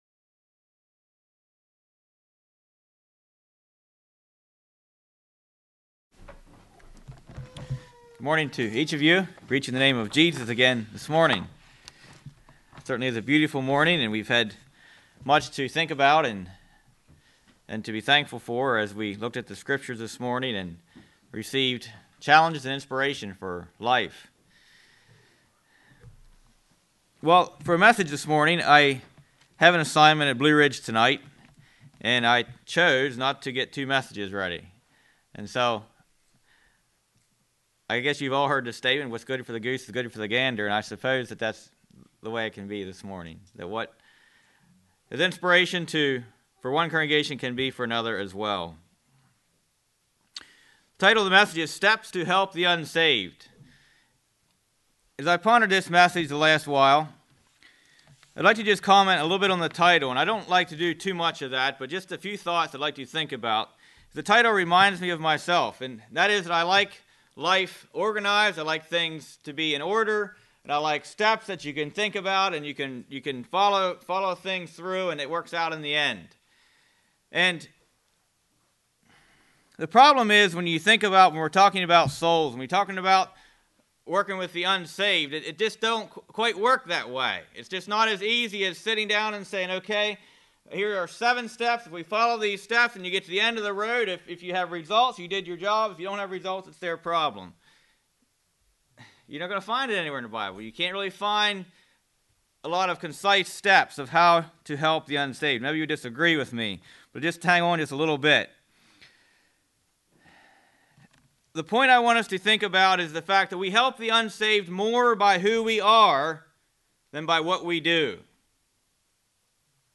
Website of Pilgrim Conference | Christian Sermons, Anabaptist e-Literature, Bible Study Booklets | Pilgrim Ministry
This message gives practical helps for anyone who is seeking the lost, by exploring the truths of the fifteenth chapter of Luke. Categories: Evangelistic